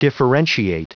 Prononciation du mot differentiate en anglais (fichier audio)
Prononciation du mot : differentiate